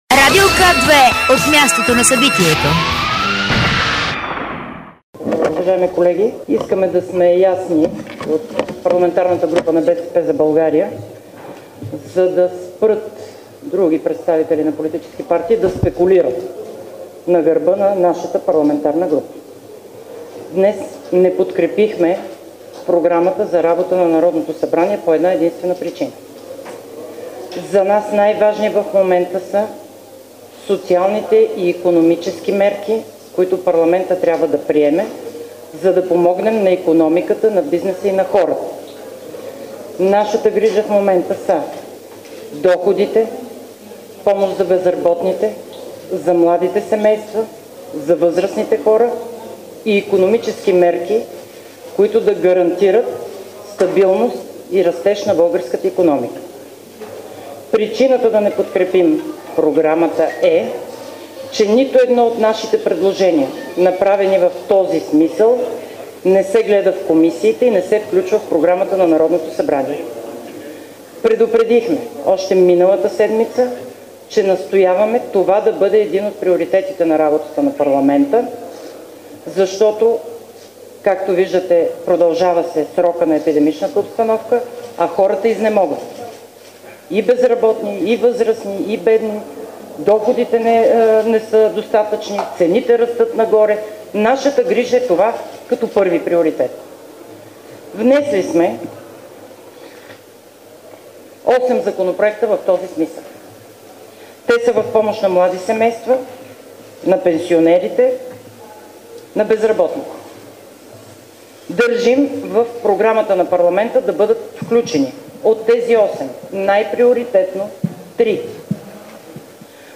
Ще Ви запознаем с инициативи на КНСБ, свързани с безопасните условия на труд, и с актуална статистика. - директно от мястото на събитието (пред сградата на КНСБ)
Директно от мястото на събитието